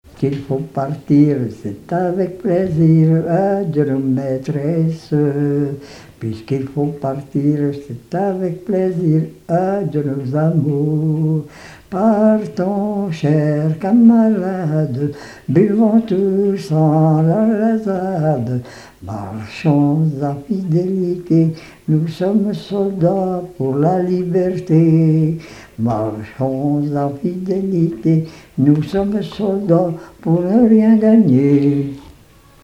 Mémoires et Patrimoines vivants - RaddO est une base de données d'archives iconographiques et sonores.
chansons et témoignages parlés
Pièce musicale inédite